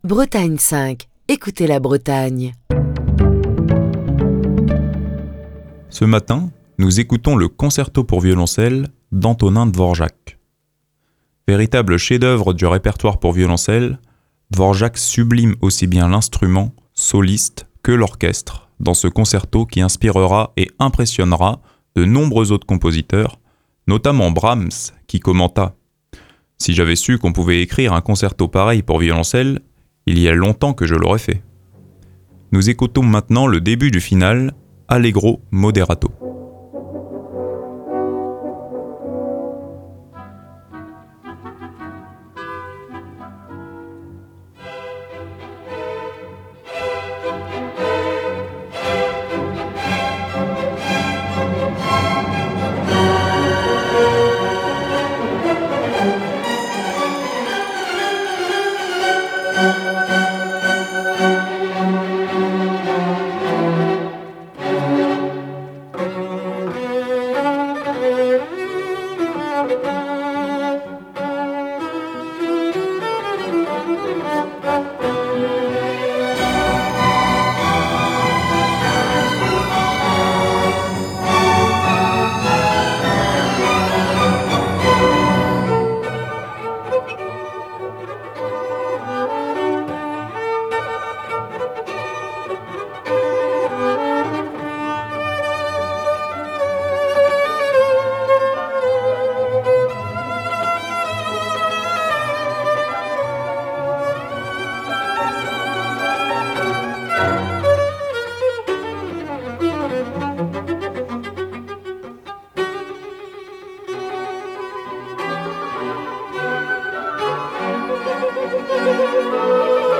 dans cette version enregistrée en 1971